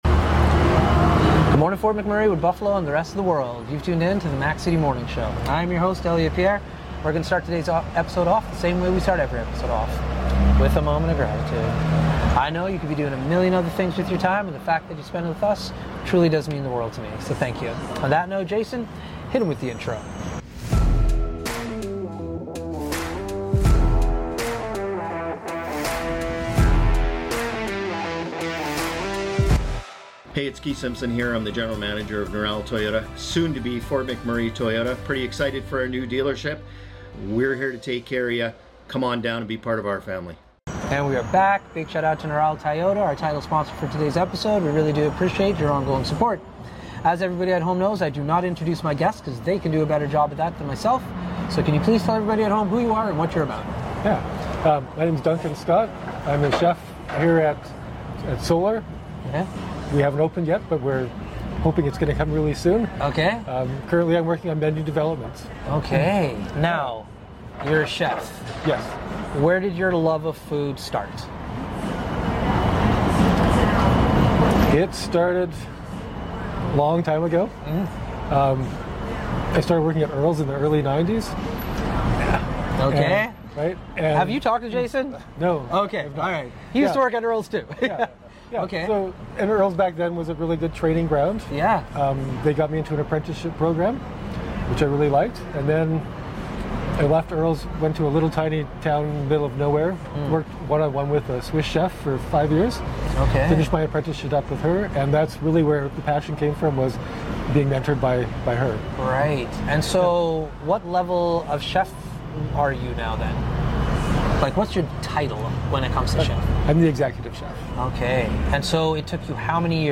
We are back on location today at Cask and Barrel in Edmonton